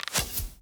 Bow Attacks Hits and Blocks
Bow Attack 1.wav